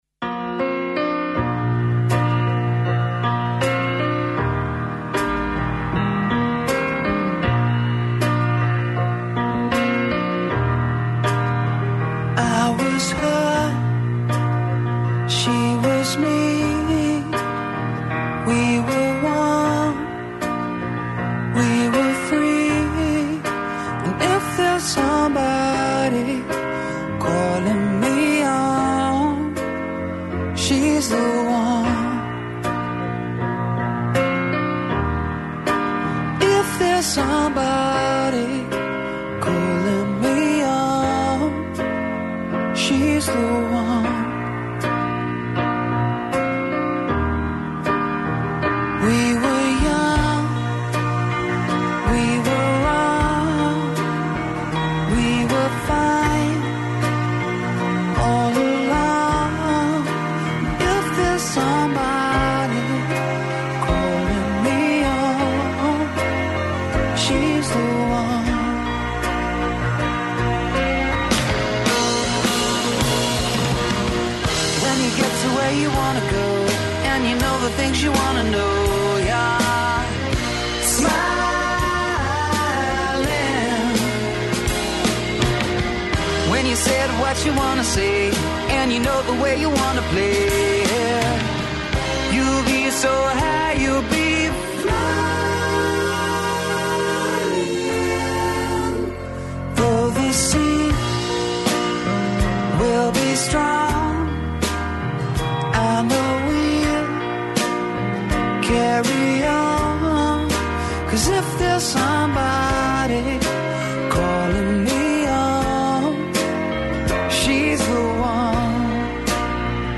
Ακούστε την εκπομπή του Νίκου Χατζηνικολάου στον ραδιοφωνικό σταθμό RealFm 97,8, την Πέμπτη 5 Μαρτίου 2026.